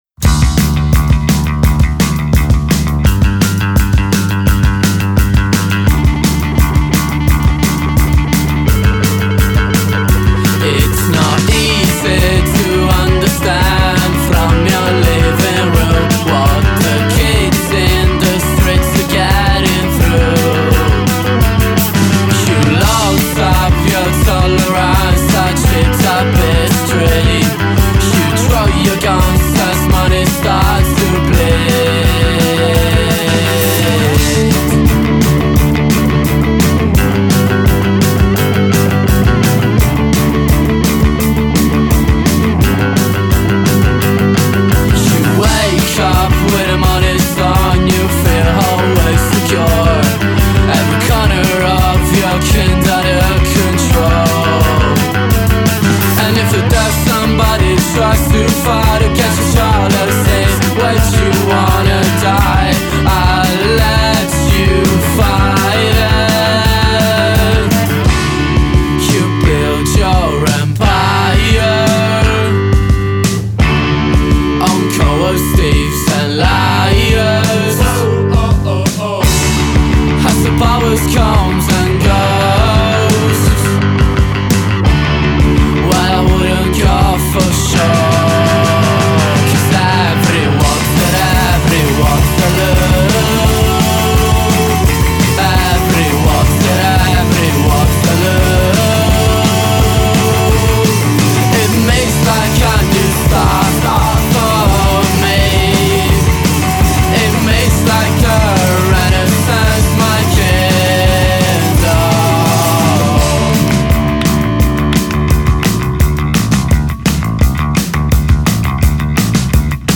play nervous and tight songs heavily influenced by new wave